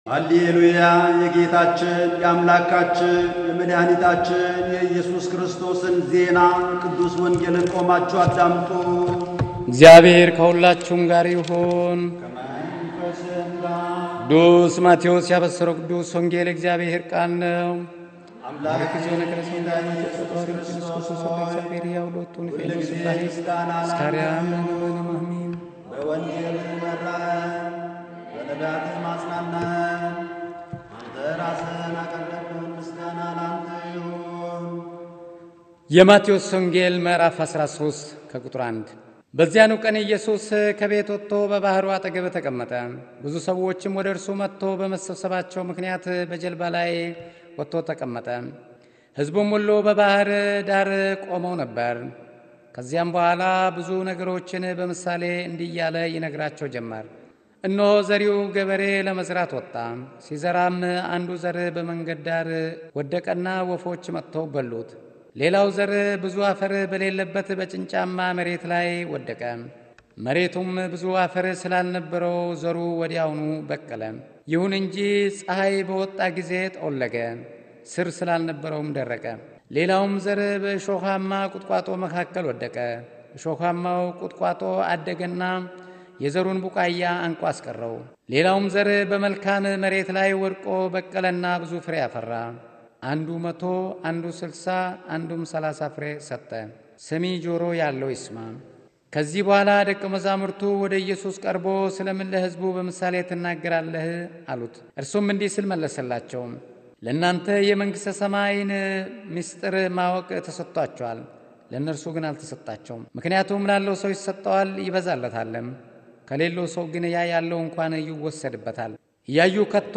የሐምሌ 9/2009 ዓ.ም. እለተ ሰንበት የቅዱስ ወንጌል አስተንትኖ